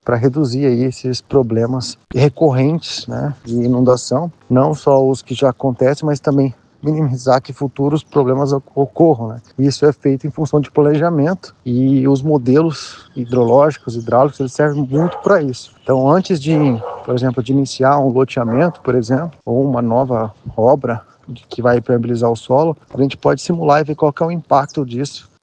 Em entrevista à FM Educativa MS